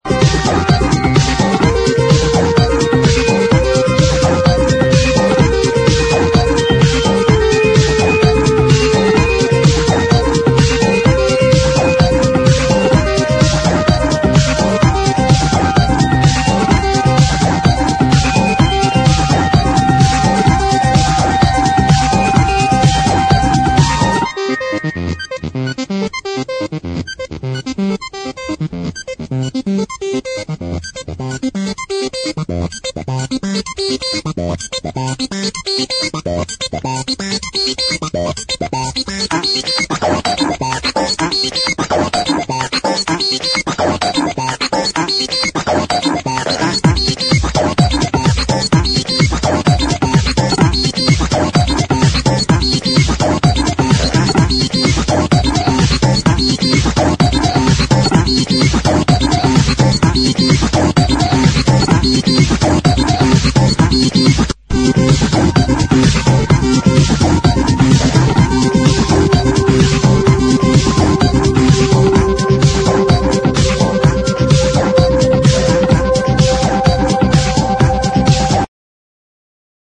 ELECTRO# BREAK BEATS / BIG BEAT